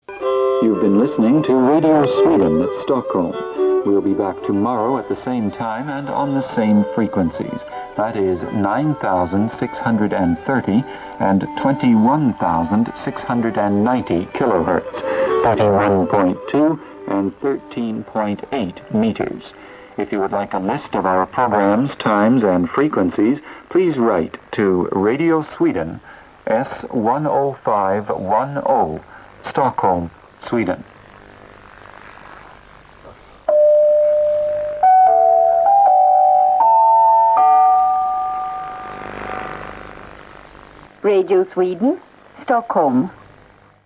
Clip-6-Radio-Sweden-Shortwave-Closedown.mp3